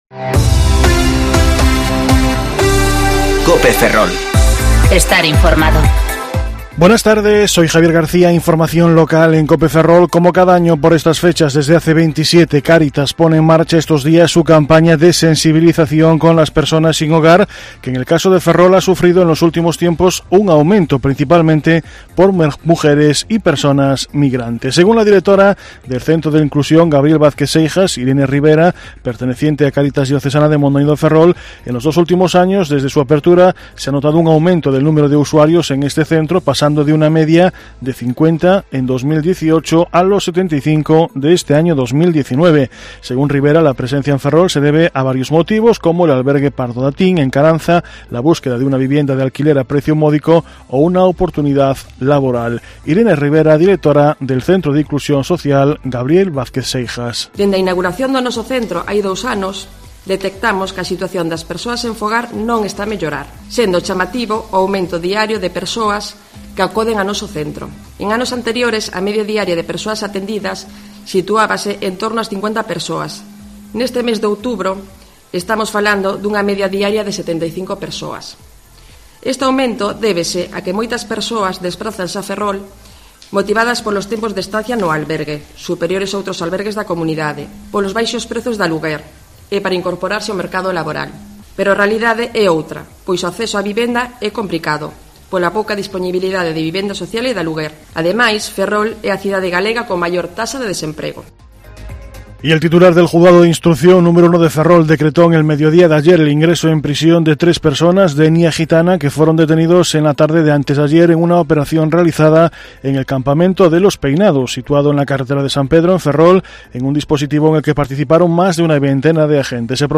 Informativo Mediodía Cope Ferrol 23/10/2019 (De 14.20 a 14.30 horas)